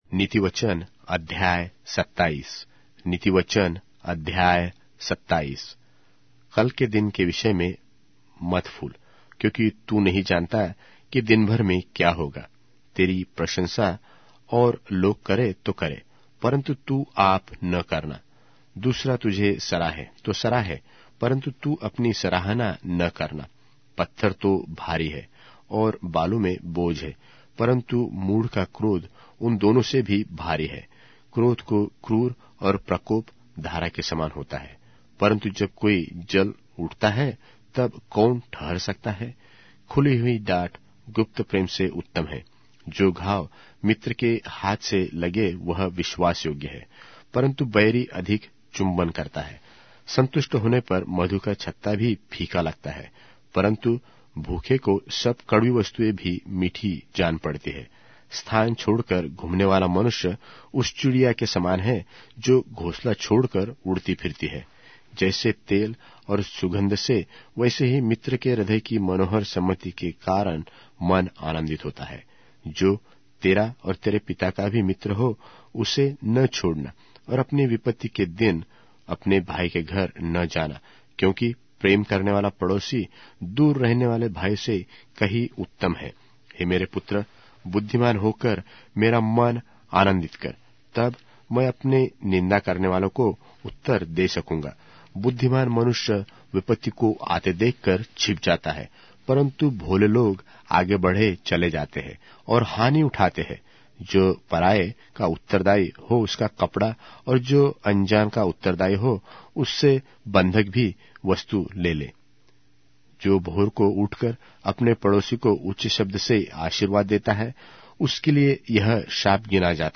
Hindi Audio Bible - Proverbs 23 in Irvml bible version